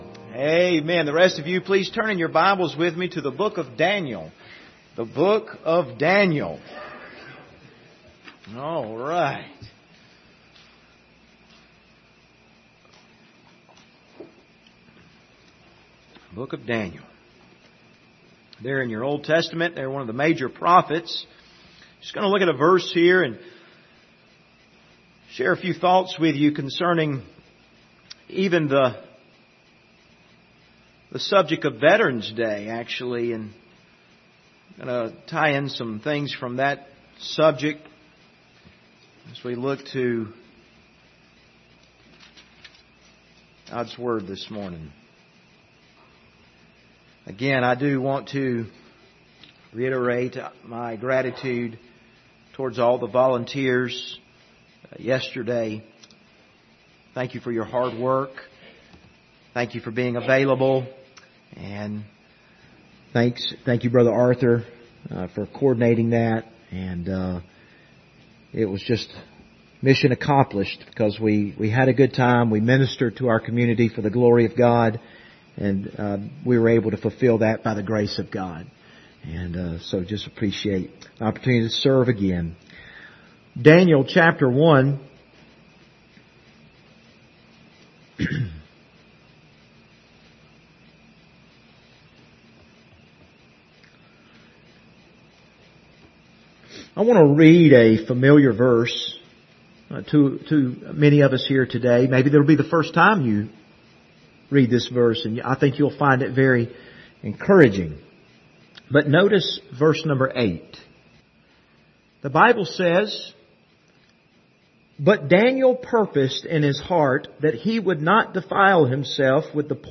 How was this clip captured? Daniel 1:8 Service Type: Sunday Morning Topics